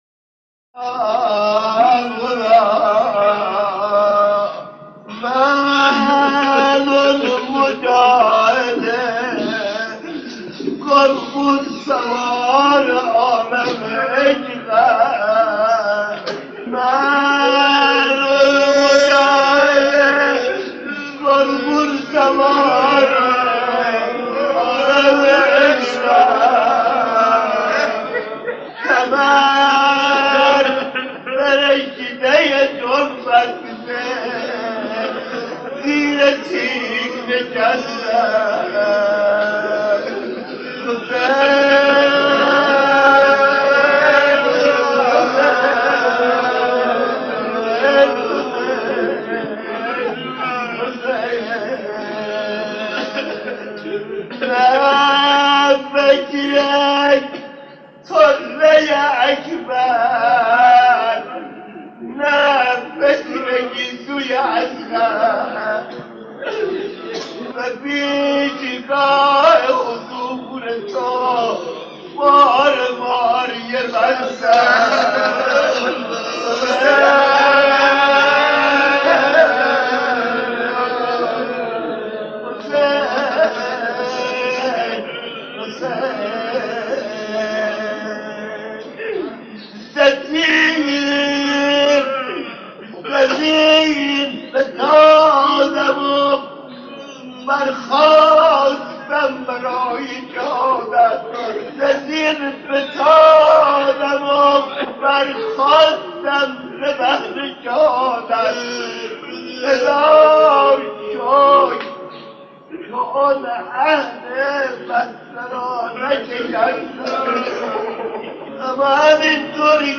یادآوری رنج عصر عاشورای حضرت اباعبدالله (ع) در قالب مصیبت‌خوانی
روضه خوانی